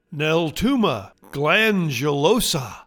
Pronounciation:
Nel-TOO-ma glan-du-LOO-sa